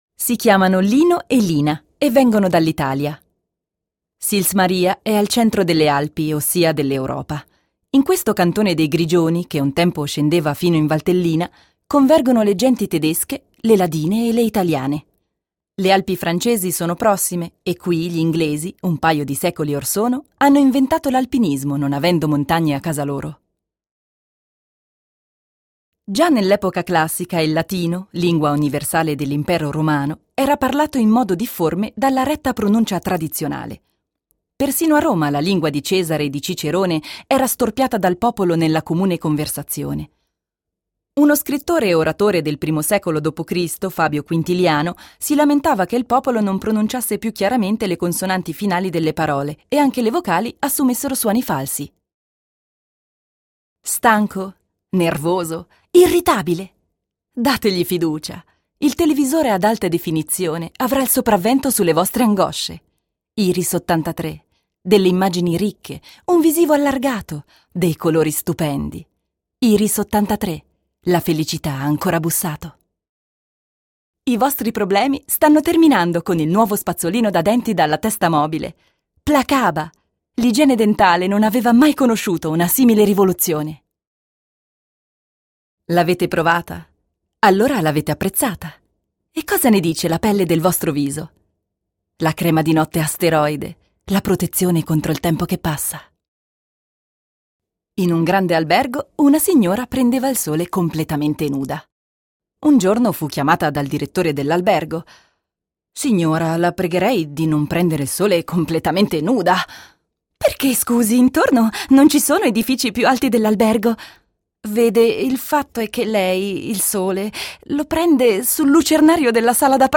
OFF-Kommentar Italienisch (CH)
Ticino